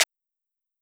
Party Monster Clap.wav